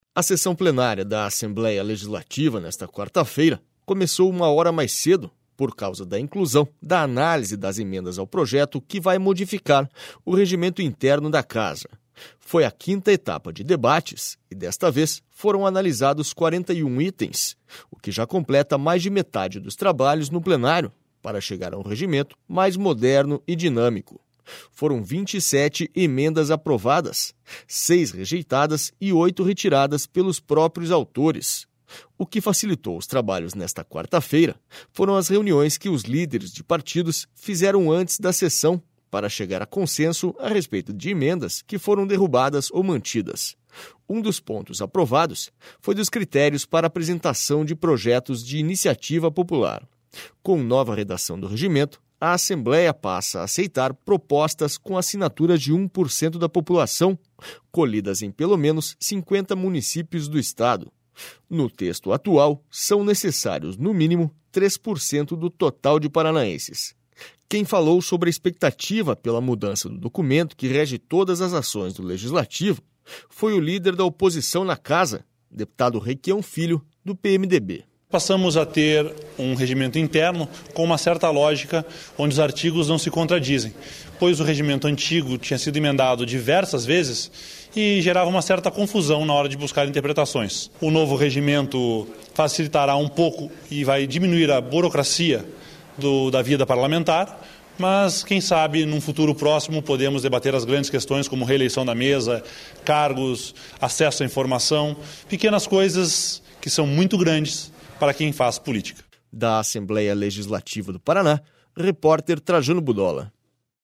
SONORA MAURICIO REQUIÃO